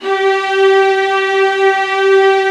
VIOLINS .8-L.wav